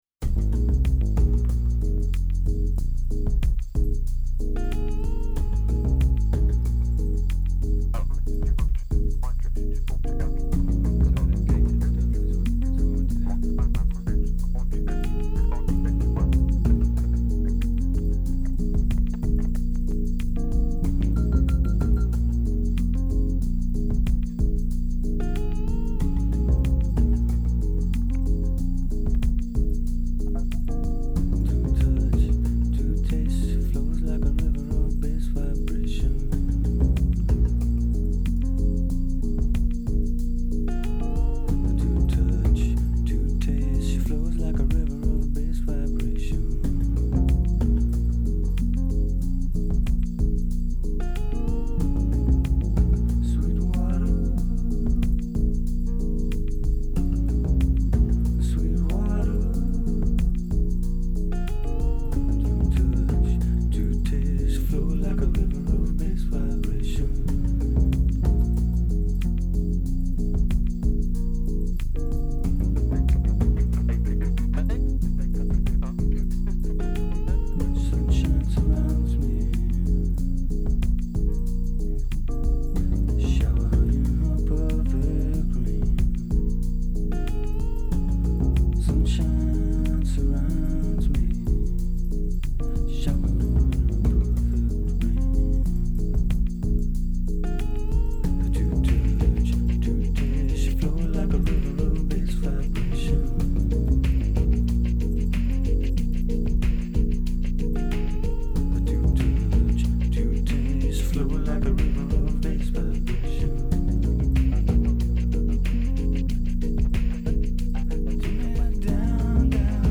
Occasionally it sounds stoned and other-wordly.
with its repetitive guitar riff and whispered vocals.